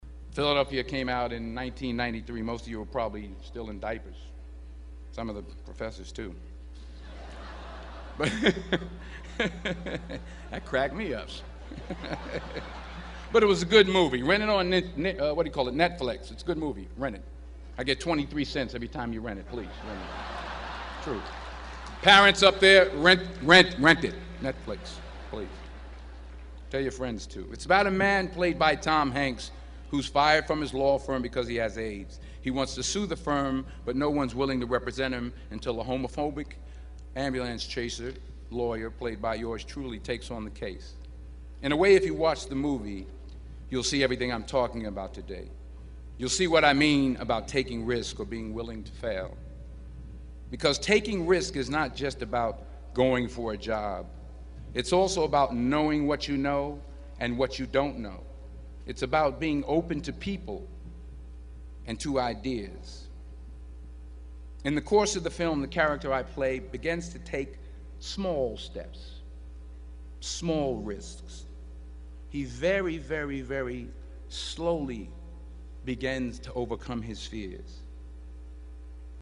公众人物毕业演讲第431期:丹泽尔2011宾夕法尼亚大学(15) 听力文件下载—在线英语听力室